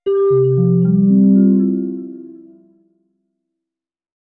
Marimba_levelUp_6.wav